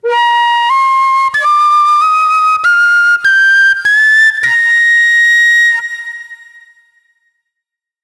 Thin whistle tocando la escala en B bemol
aerófono
celta
flauta
madera